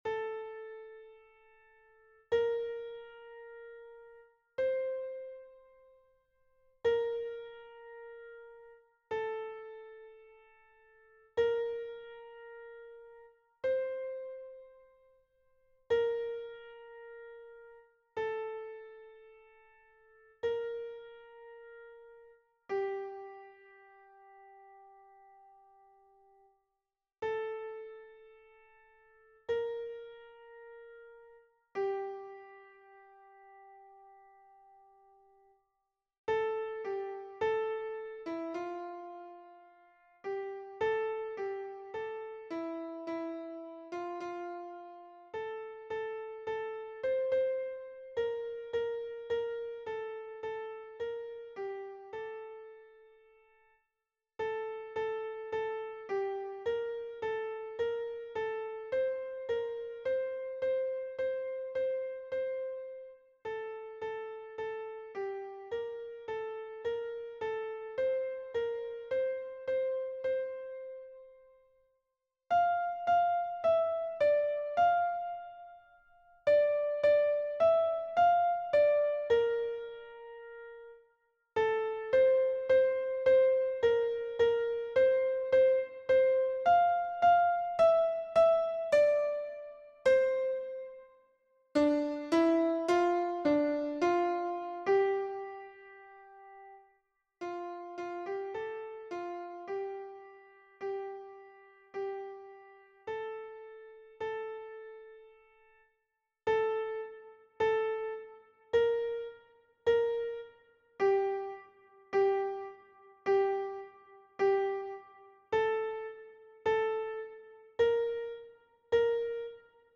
MP3 version piano
Ténor